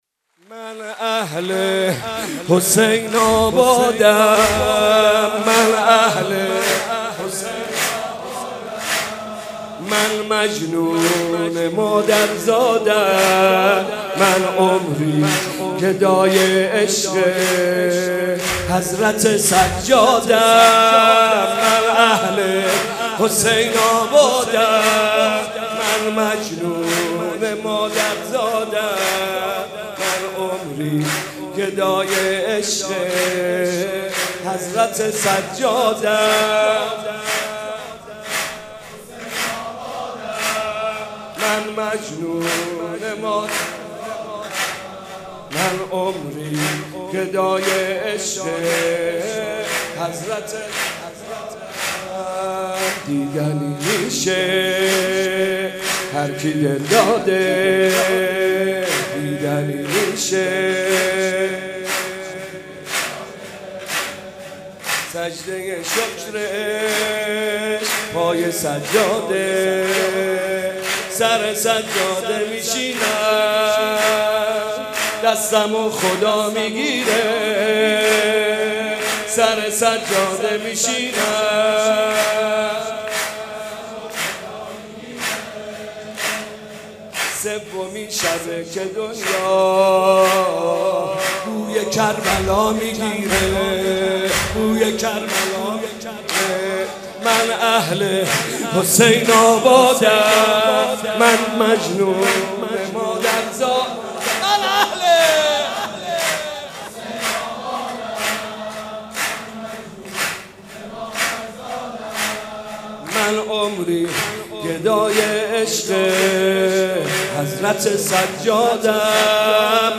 مناسبت : ولادت امام سجاد علیه‌السلام
سرود